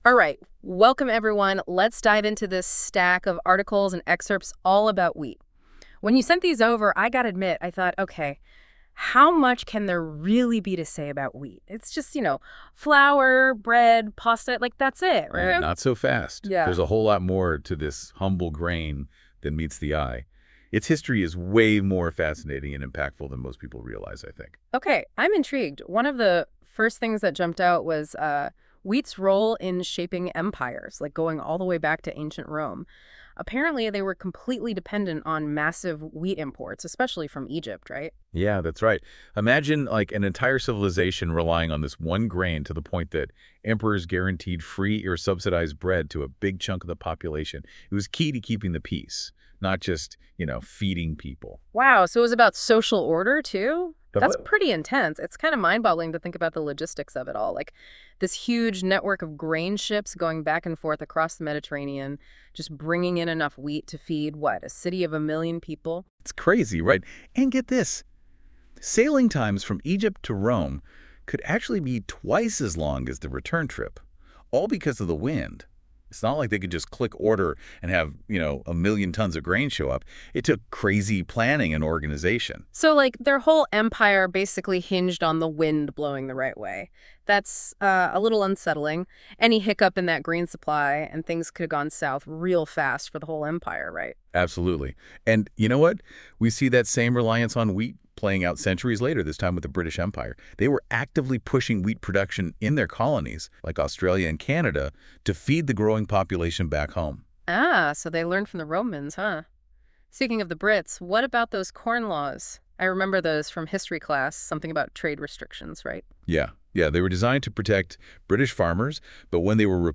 This week, I asked Google Notebook LM to create a podcast on wheat.